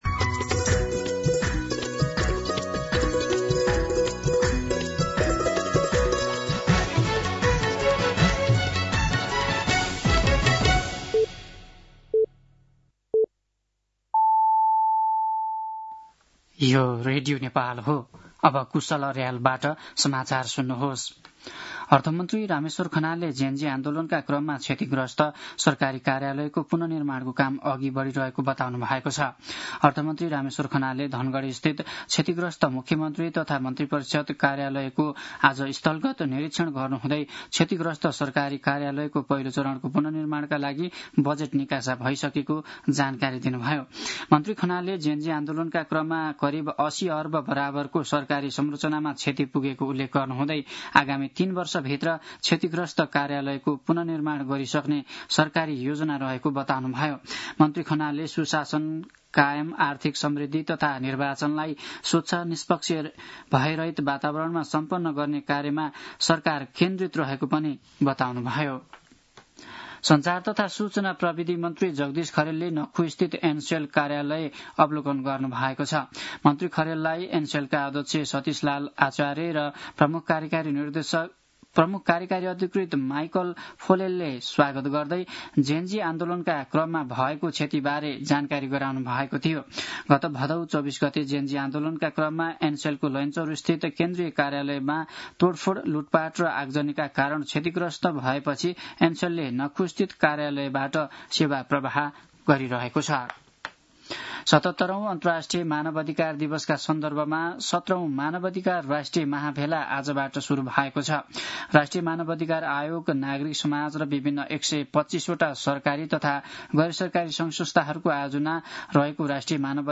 दिउँसो ४ बजेको नेपाली समाचार : २२ मंसिर , २०८२